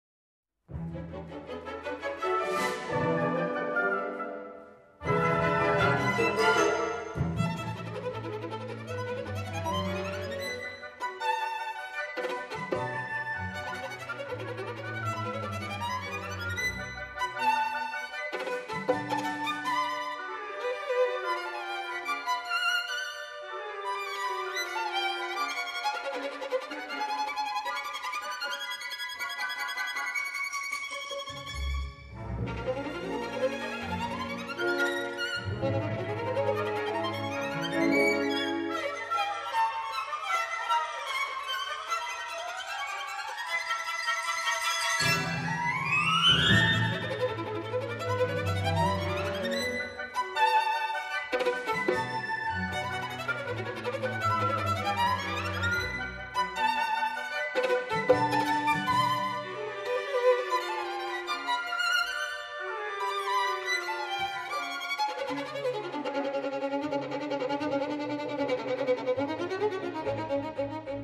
Polska muzyka wirtuozowska
Opracowanie na skrzypce i orkiestrę